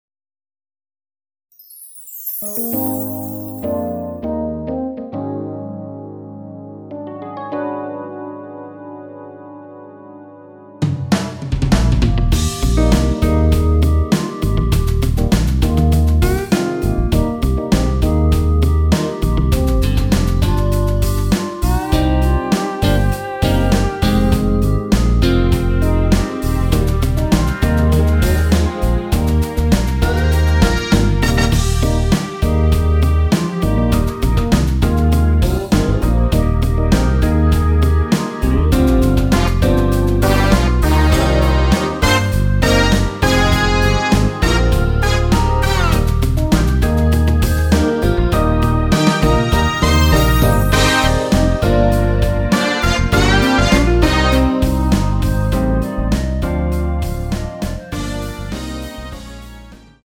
원키에서(-2)내린 멜로디 포함된 MR입니다.(미리듣기 확인)
Eb
노래방에서 노래를 부르실때 노래 부분에 가이드 멜로디가 따라 나와서
앞부분30초, 뒷부분30초씩 편집해서 올려 드리고 있습니다.
중간에 음이 끈어지고 다시 나오는 이유는